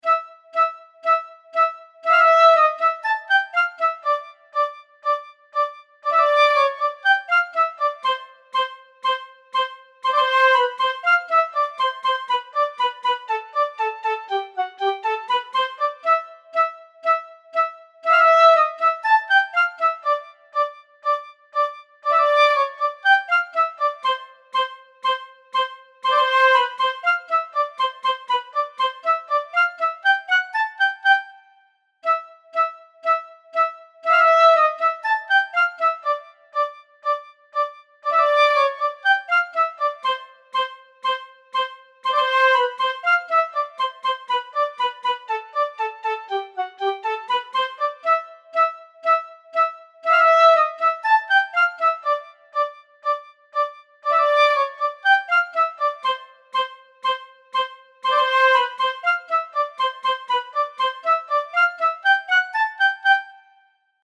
Woodwind.mp3